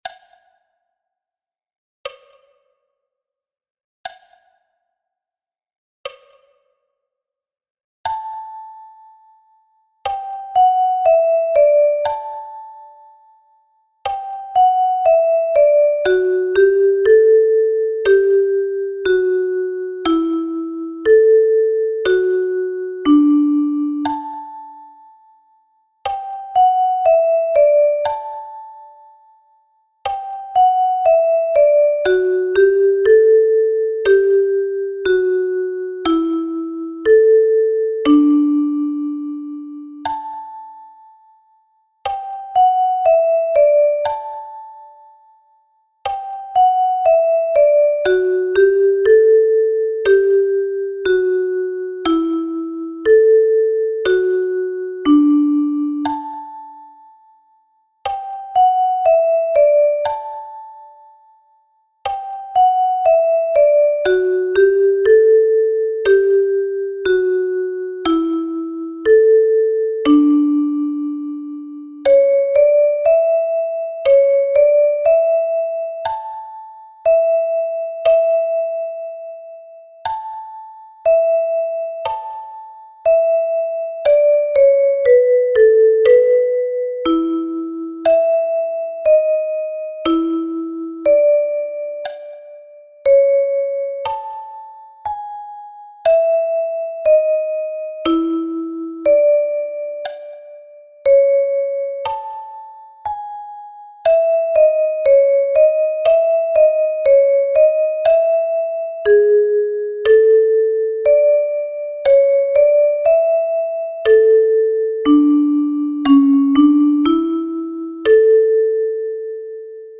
notiert als Duette für die chromatische Mundharmonika.